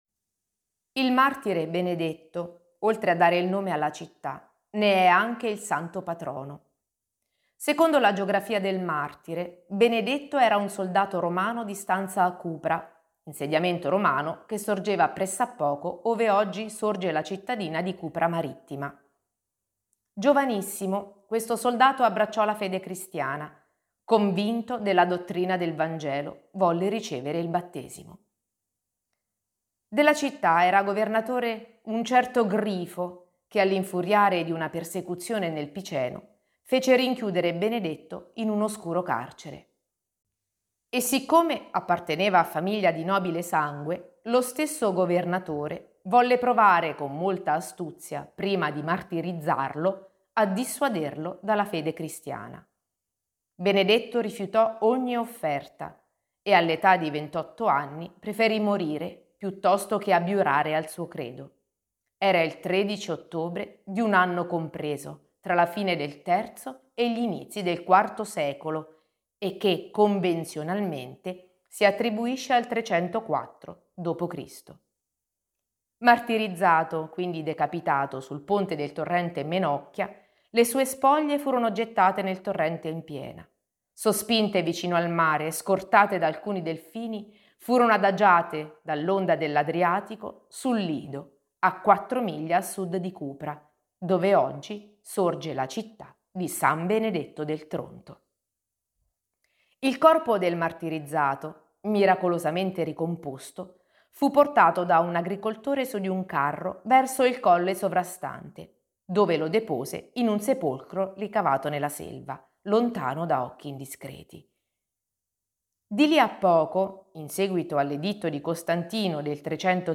RIPRODUCI L'AUDIOGUIDA COMPLETA RIPRODUCI FERMA oppure LEGGI LA STORIA GUARDA IL VIDEO LIS